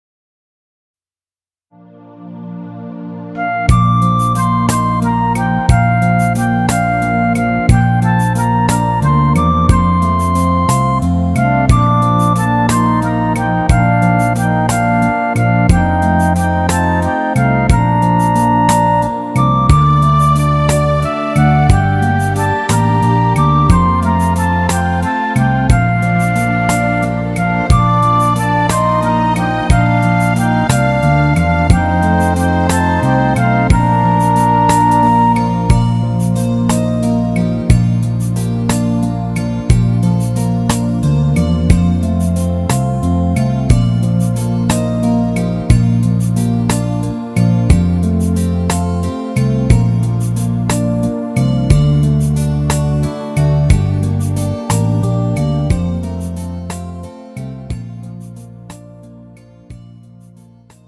음정 Bb 원키
장르 가요 구분 Pro MR
Pro MR은 공연, 축가, 전문 커버 등에 적합한 고음질 반주입니다.